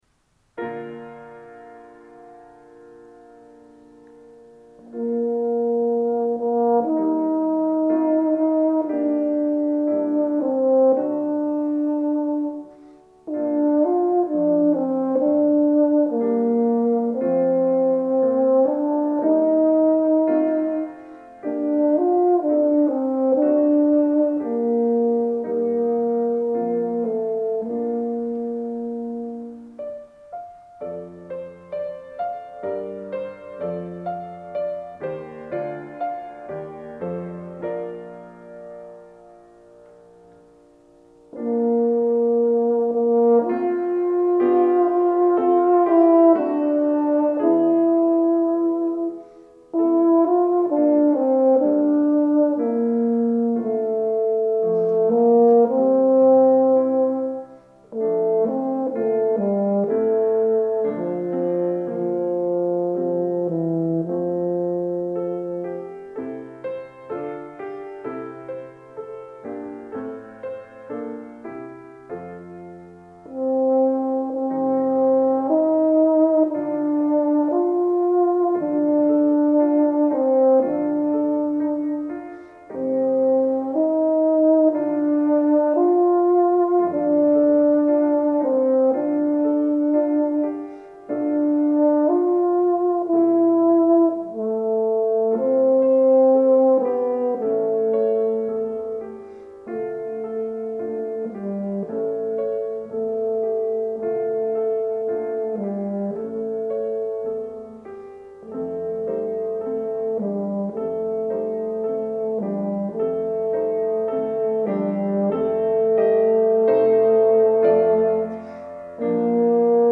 For Euphonium Solo